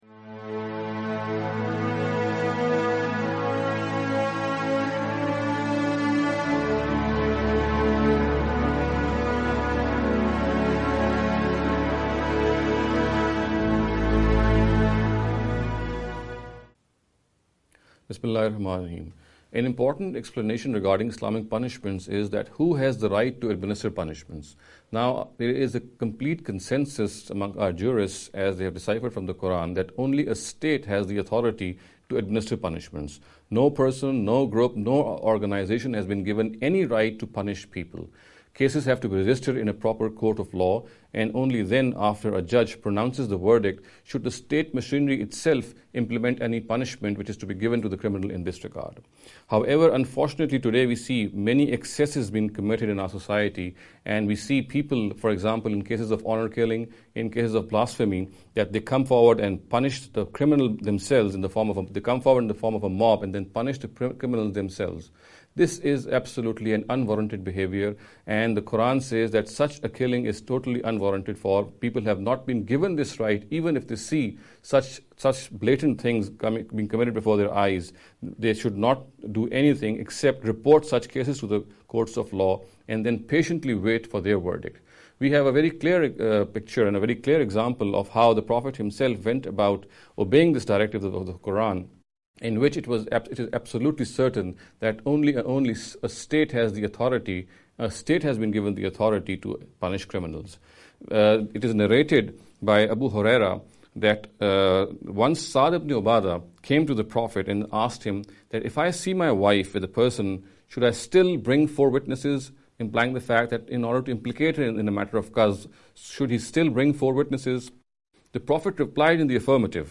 This lecture series will deal with some misconception regarding the Islamic Punishments.